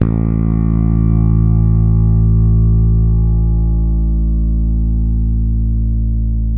-JP ROCK A.2.wav